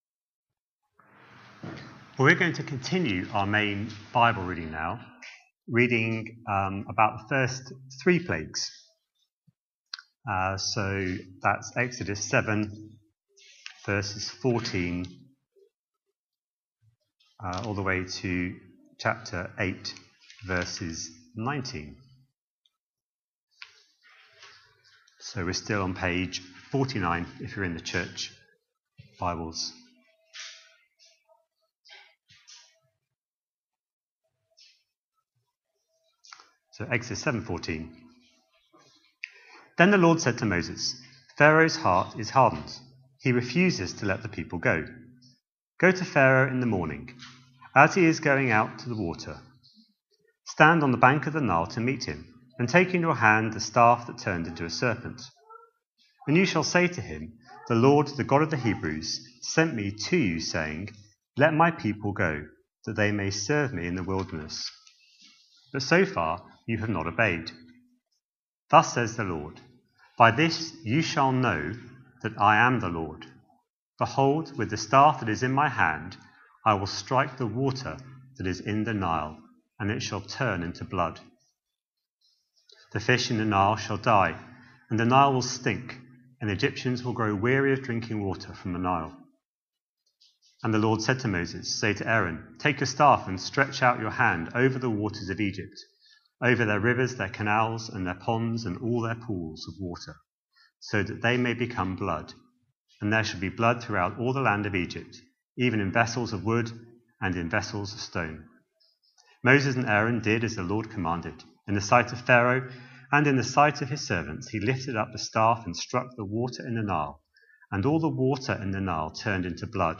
A sermon preached on 3rd August, 2025, as part of our Exodus series.